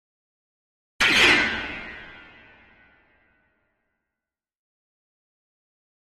Piano Jarring Piano Hit, High Tones - Double